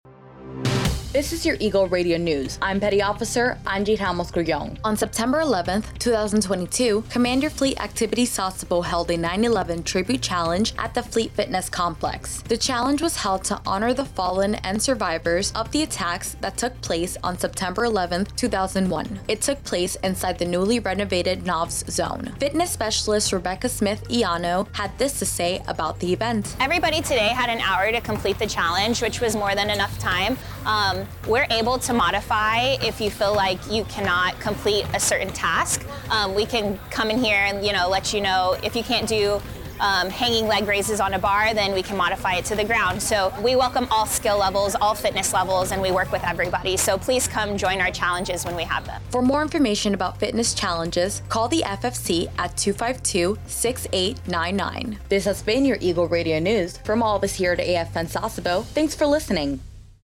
13-14 SEPT 22 TNEWSCAST